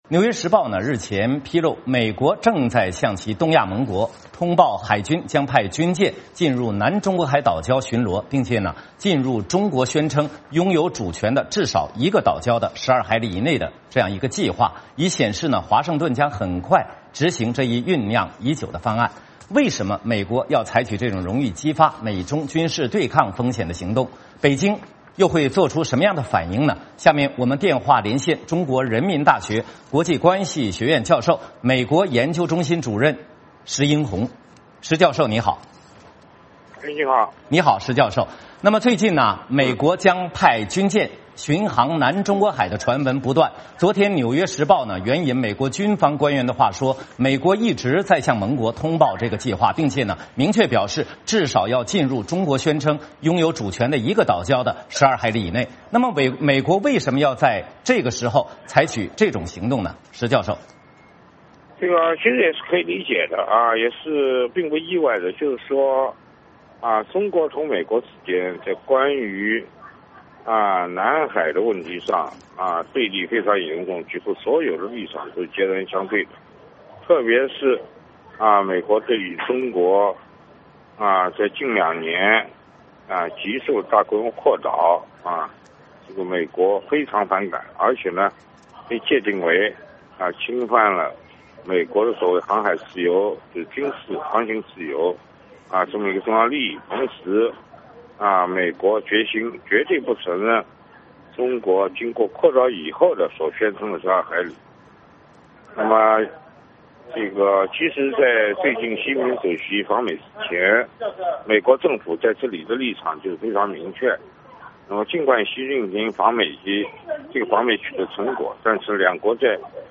我们电话连线中国人民大学国际关系学院教授、美国研究中心主任时殷弘。